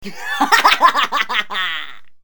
男声怪笑音效_人物音效音效配乐_免费素材下载_提案神器